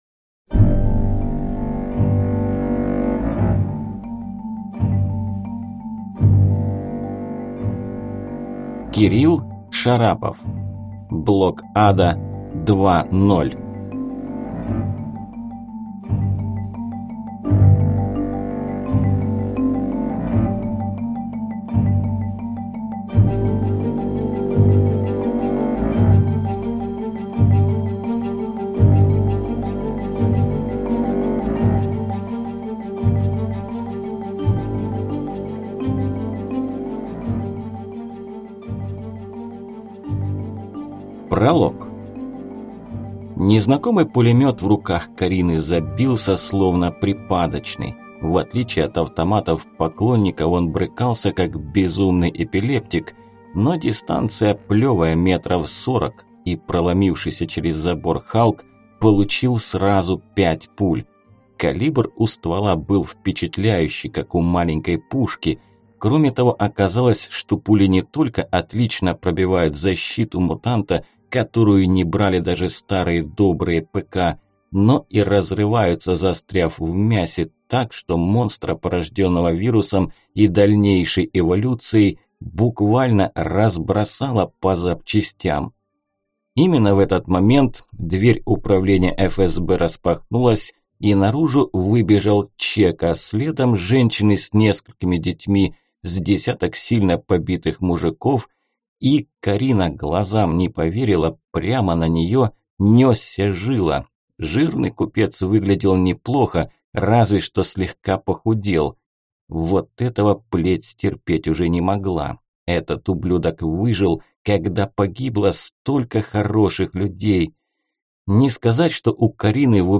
Аудиокнига БлокАда-2.0 | Библиотека аудиокниг